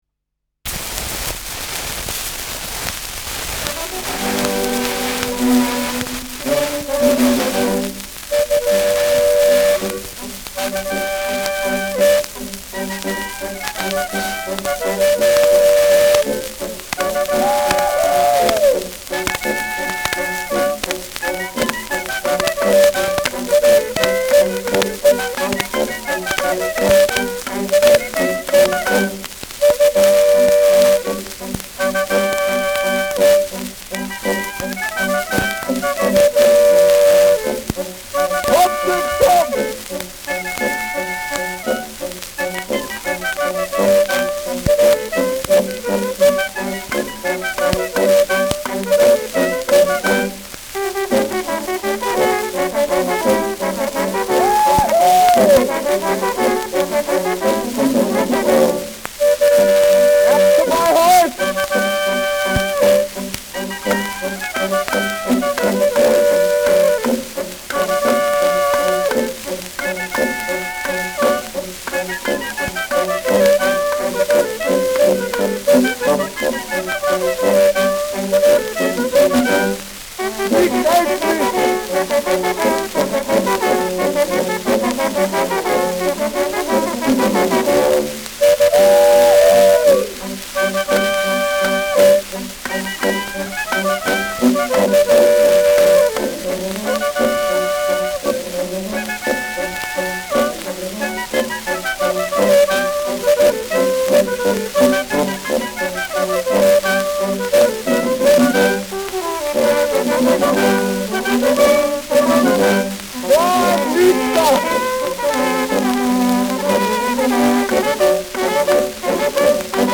Schellackplatte
zu Beginn starkes Rauschen : durchgehend präsentes Rauschen : gelegentlich präsentes Knacken
Mit Juchzern, Zwischenrufen und Klopfgeräuschen.